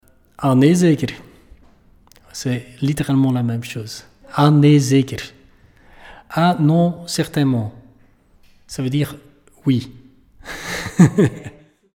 Ah nee zeker play all stop prononciation Ah nee zeker ?